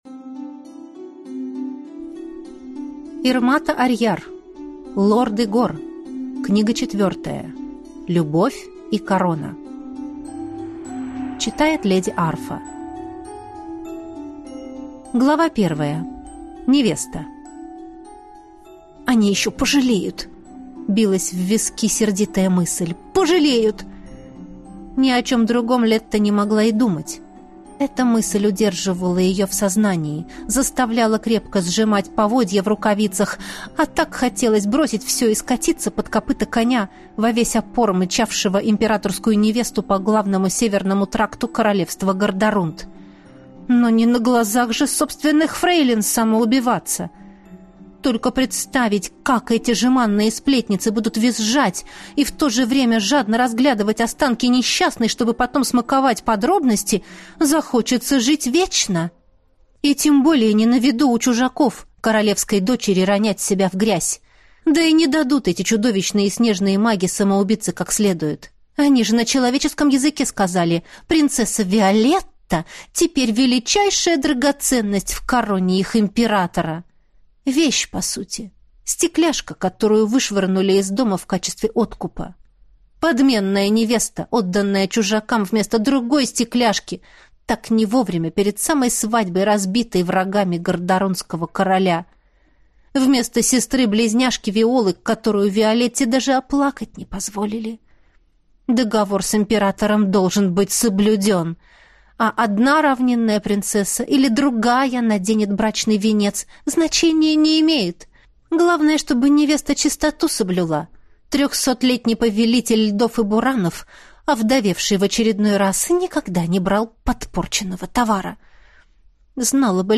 Аудиокнига Любовь и корона. Книга 4 | Библиотека аудиокниг